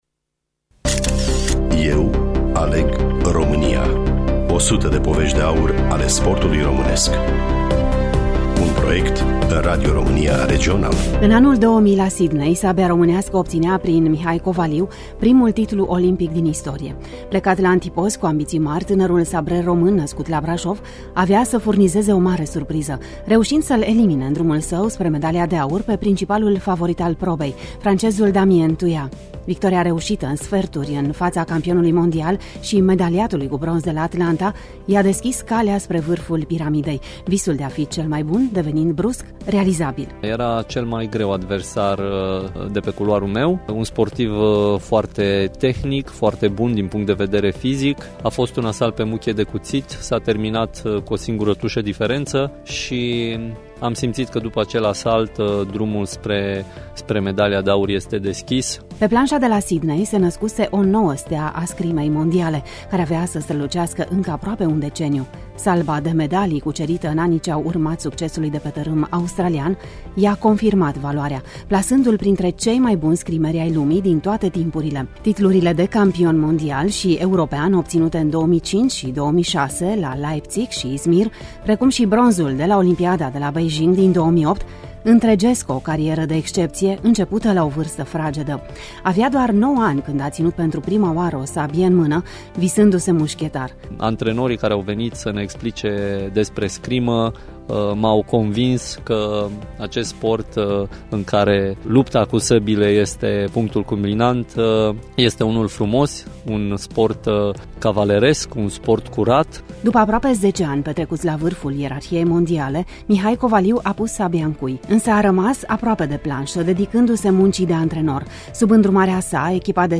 Studioul: Radio România Tg.-Mureş